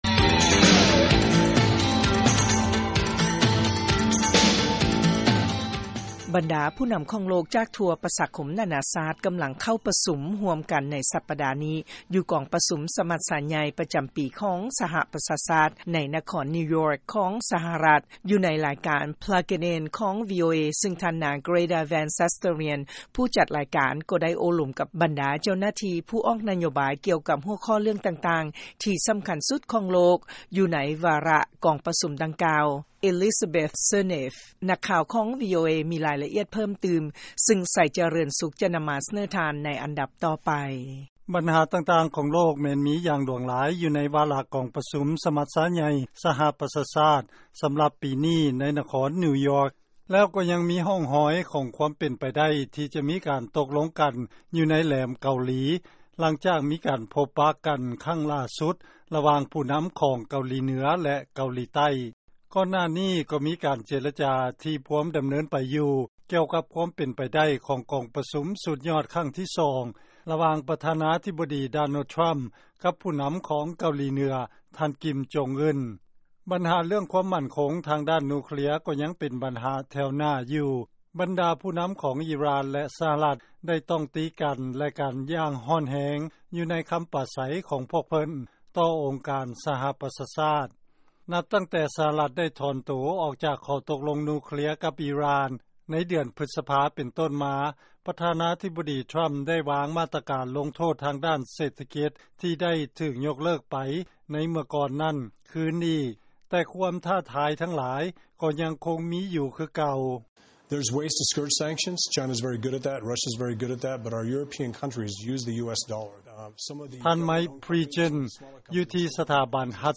ເຊີນຟັງລາຍງານ ບັນຫາຄວາມໝັ້ນຄົງ ດ້ານພະລັງງານ ສາທາລະນະສຸກຂອງໂລກ ລ້ວນແຕ່ເປັນ ບູລິມະສິດ ໃນກອງປະຊຸມ ສະມັດຊາໃຫຍ່ ສປຊ